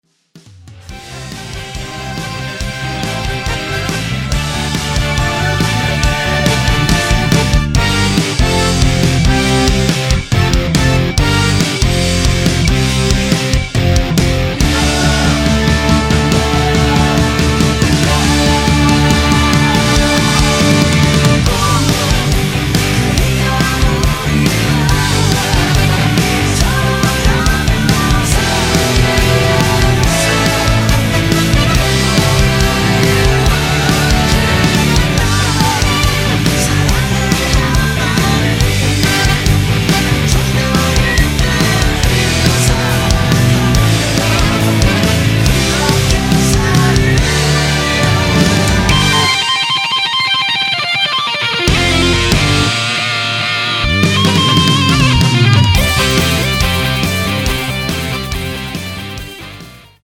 원키에서(-1)내린 코러스 포함된 MR입니다.
앞부분30초, 뒷부분30초씩 편집해서 올려 드리고 있습니다.